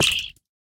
Minecraft Version Minecraft Version snapshot Latest Release | Latest Snapshot snapshot / assets / minecraft / sounds / mob / pufferfish / hurt2.ogg Compare With Compare With Latest Release | Latest Snapshot
hurt2.ogg